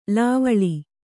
♪ lāvaḷi